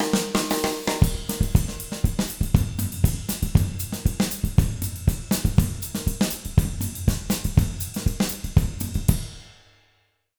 240SAMBA05-R.wav